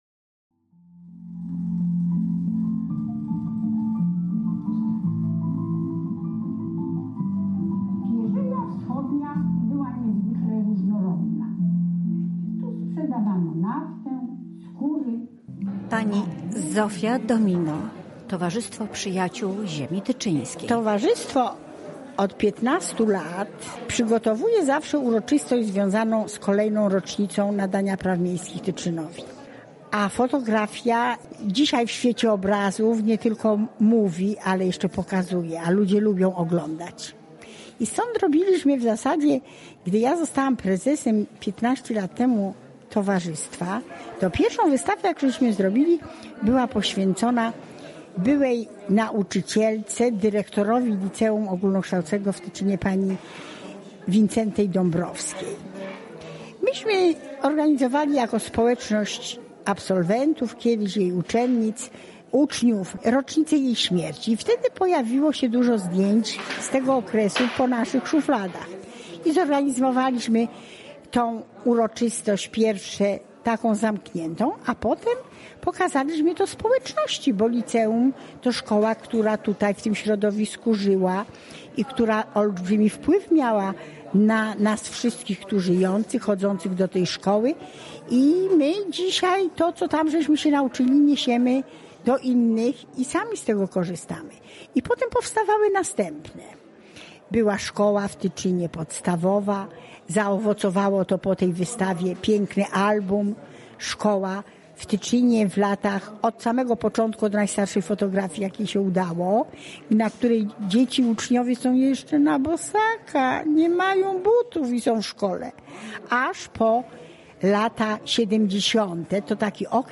Wydarzenie odbyło się w Centrum Dziedzictwa Kulturowego i Informacji Turystycznej "Spichlerz" w Tyczynie.